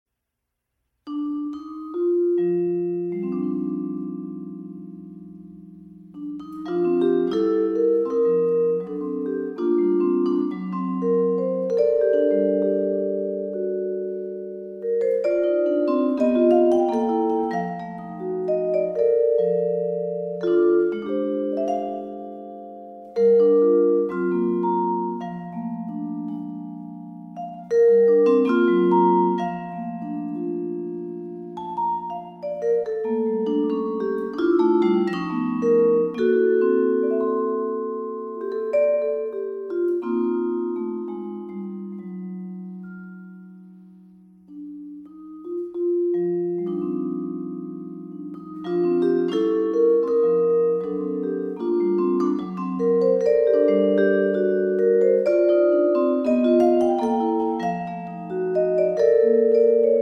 vibraphone